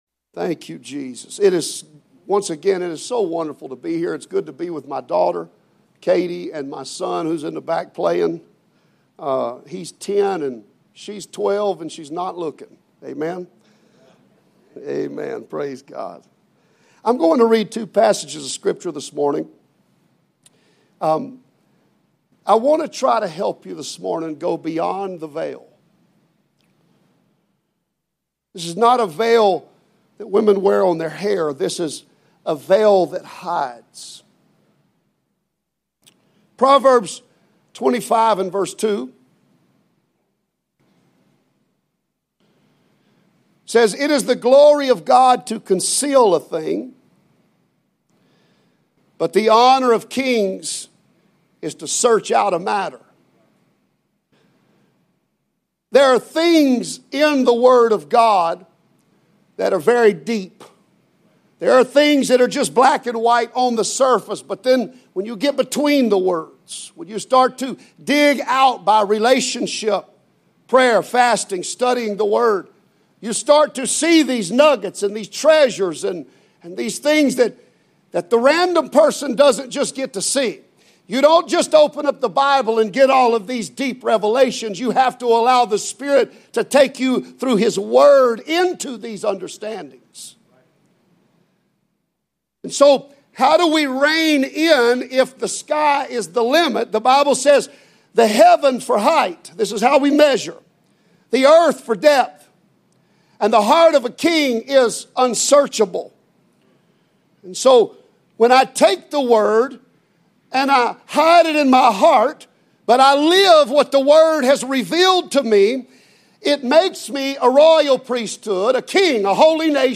Sermons | Cross Church Kansas City
Guest Speaker Proverbs 22:3-6, 25:2-4, Acts 2:37-42, Romans 6:1-15, 8:1, Matthew 28:18-20, Colossians 2:9, Micah 7:8, 1 Corinthians 15:53-58